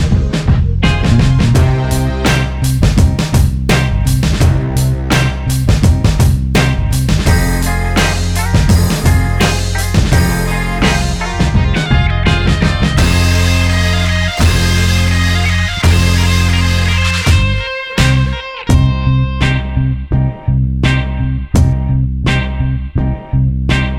no Backing Vocals R'n'B / Hip Hop 3:04 Buy £1.50